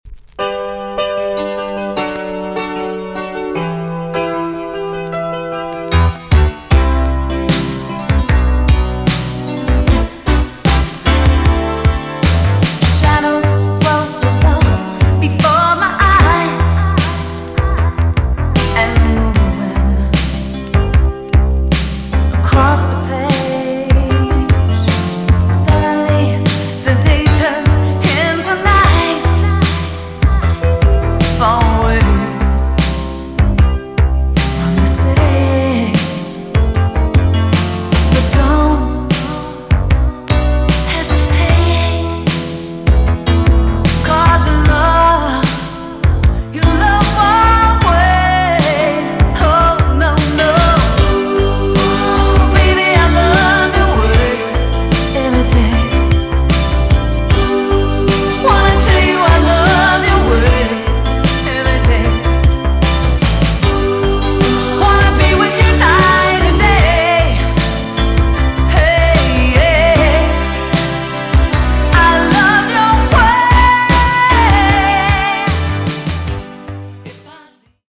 EX 音はキレイです。
WICKED JAMAICAN SOUL TUNE!!